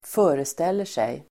Uttal: [²f'ö:restel:er_sej]